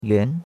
yuan2.mp3